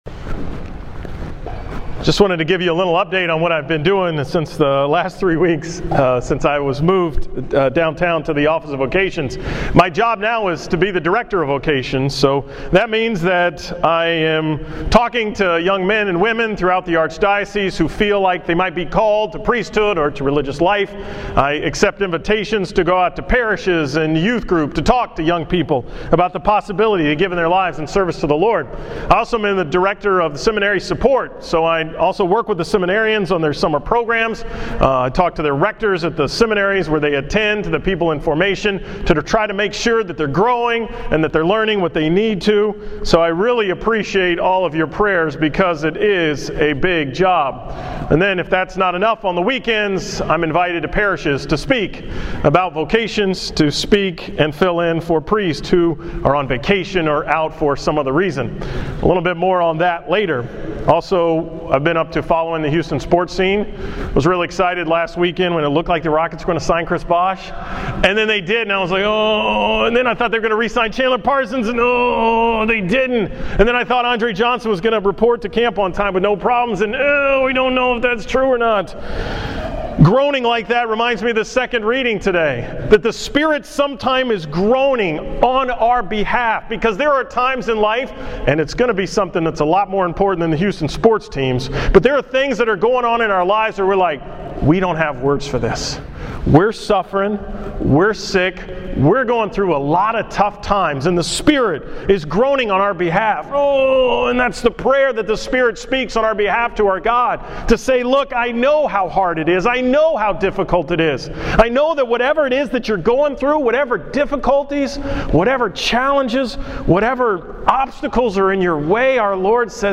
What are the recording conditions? From the 5 pm Mass at St. Thomas More